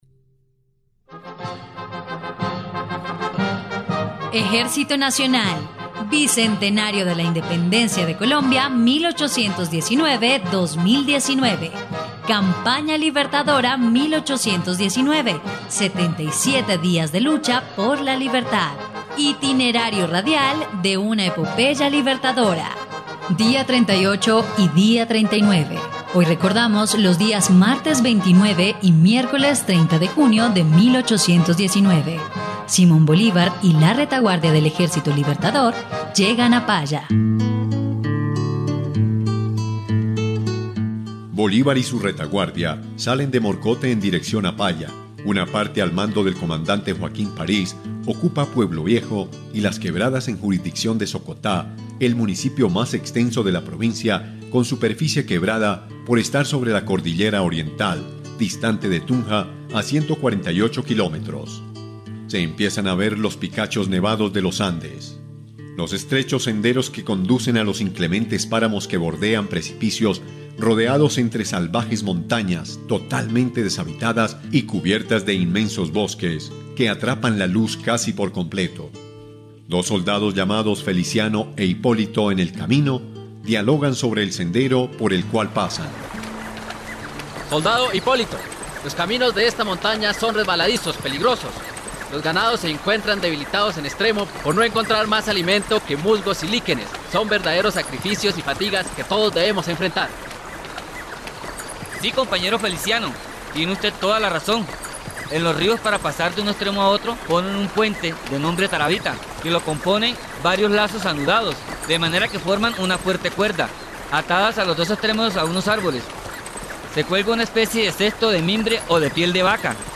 dia_38_y_39_radionovela_campana_libertadora.mp3